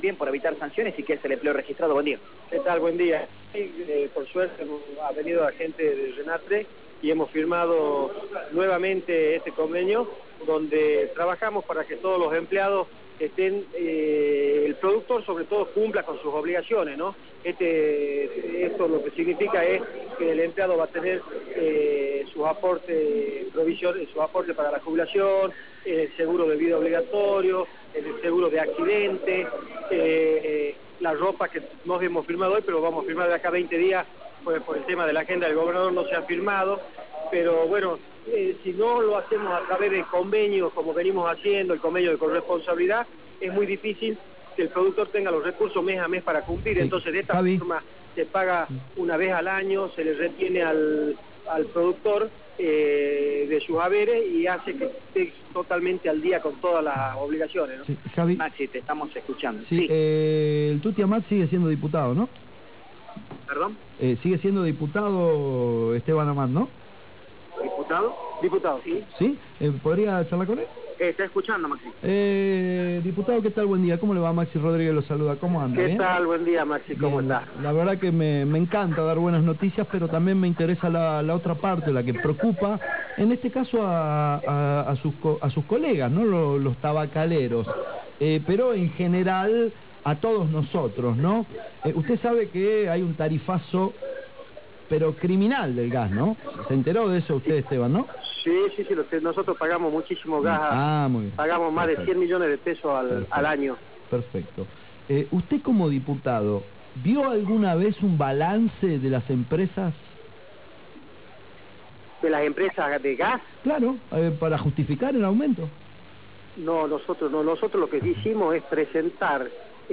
Reportaje
en AM840